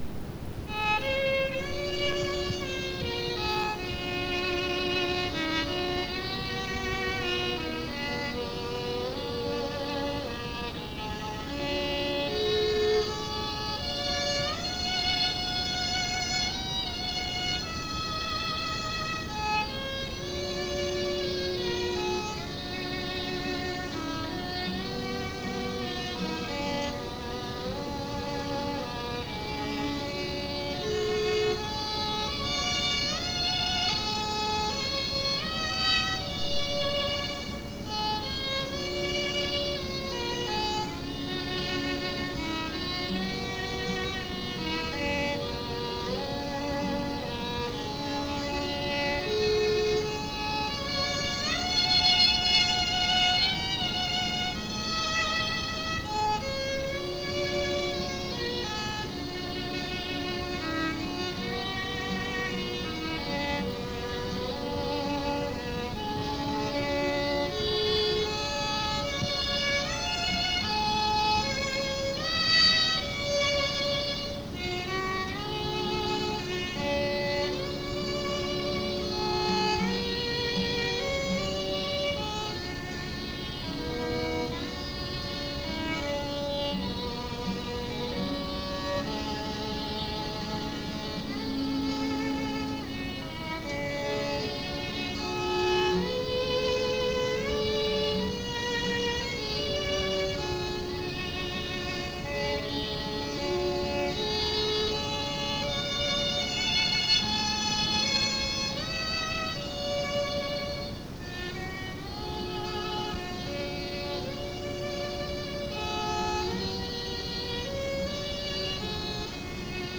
and I played violin
accompanied them on guitar.
the characteristic cassette tape hiss.